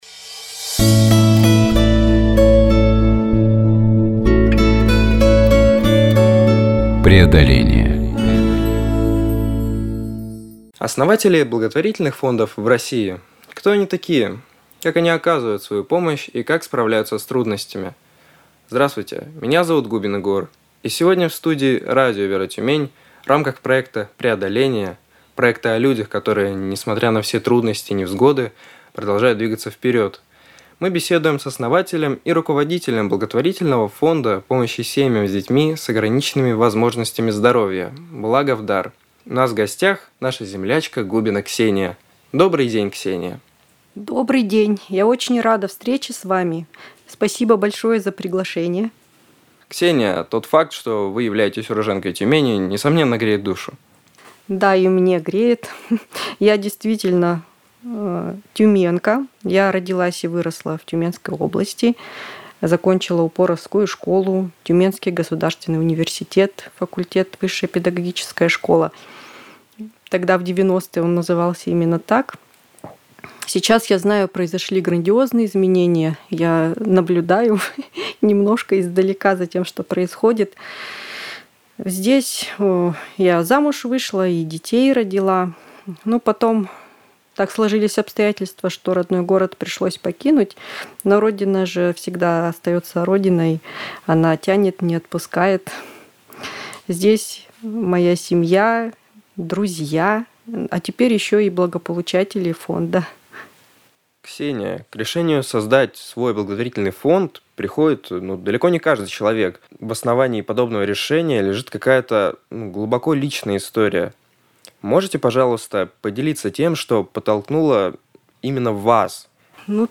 Эфир на радио Вера Тюмень